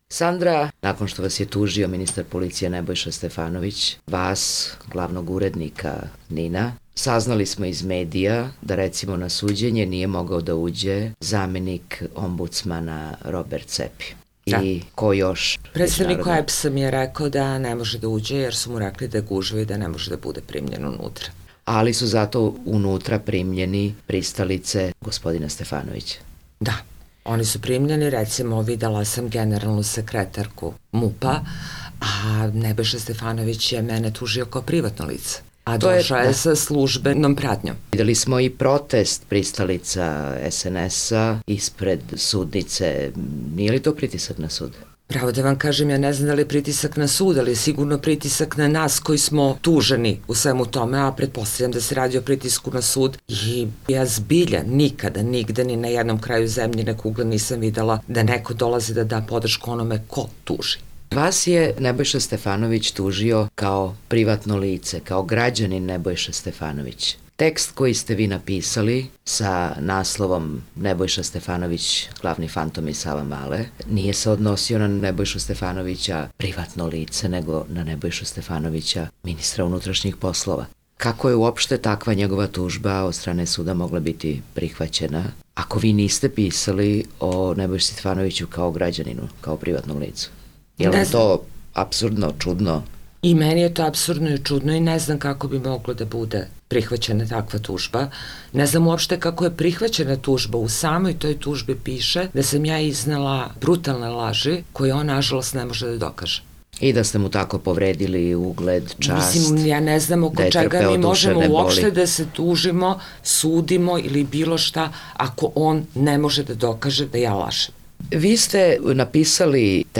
Intervju nedelje